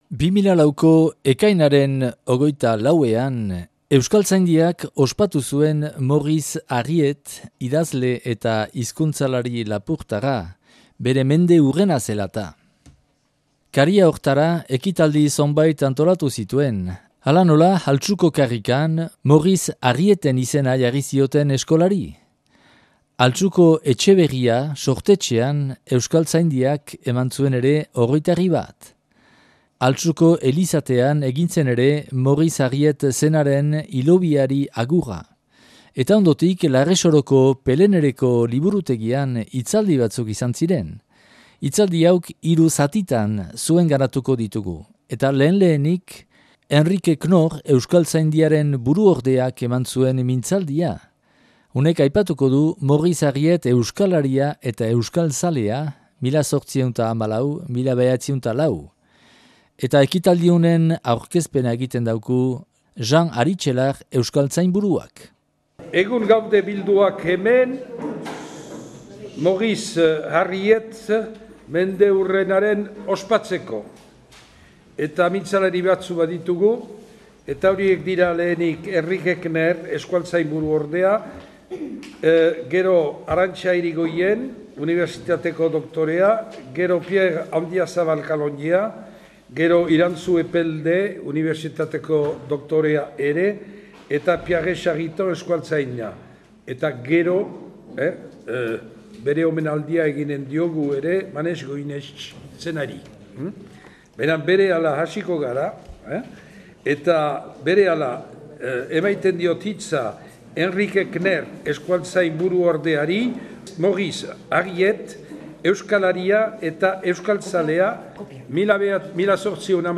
Larresoron grabatua 2004. ekainaren 4an